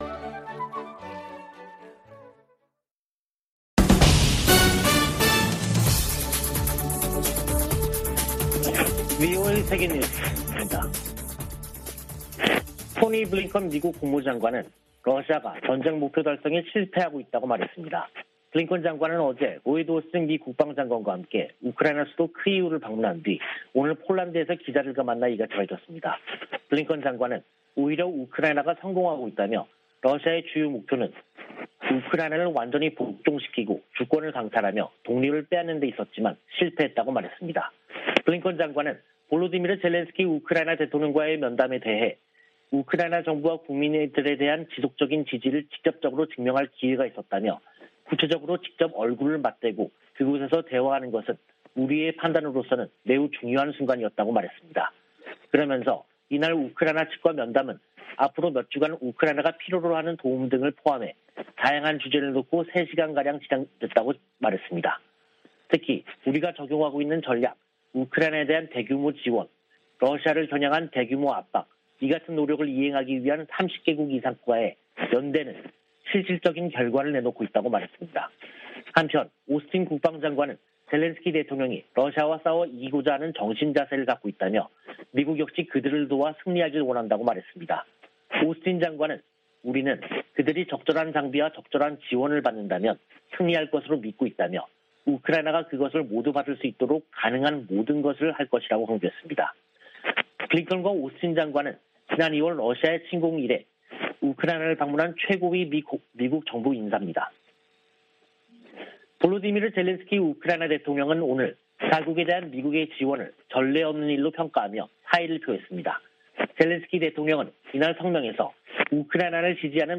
VOA 한국어 간판 뉴스 프로그램 '뉴스 투데이', 2022년 4월 25일 2부 방송입니다.